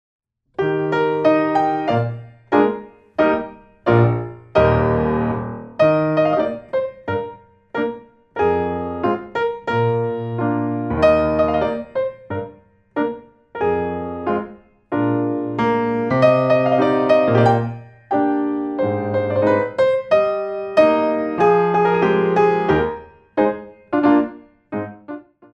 2 bar intro 4/4
32 bars